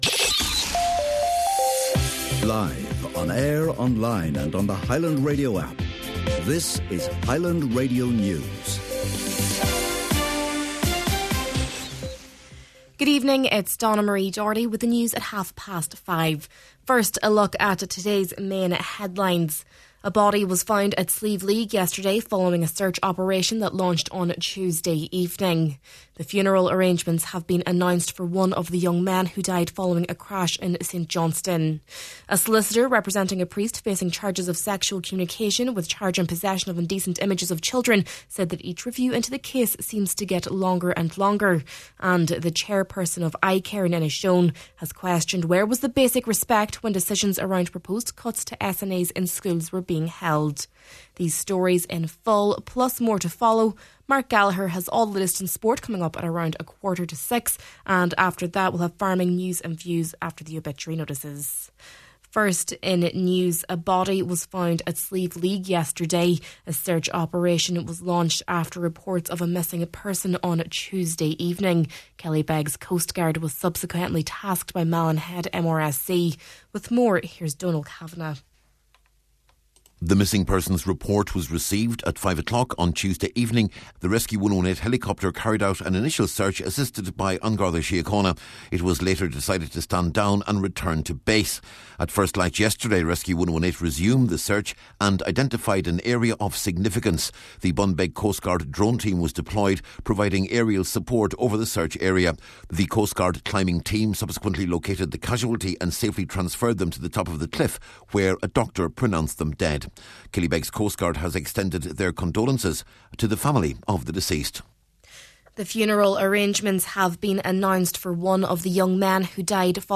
Main Evening News, Sport, Farming News and Obituary Notices – Thursday, February 26th